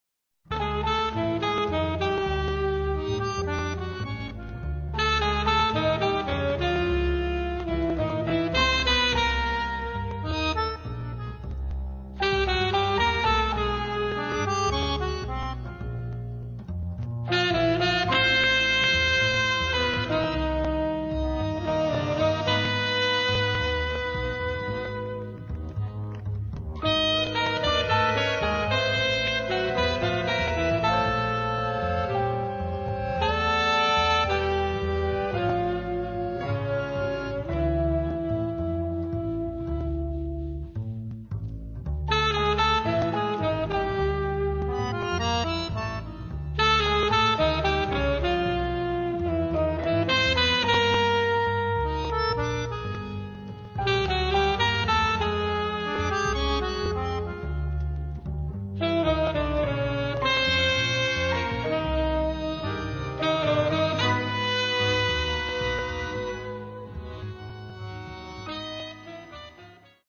sax
fisarmonica
piano
contrabbasso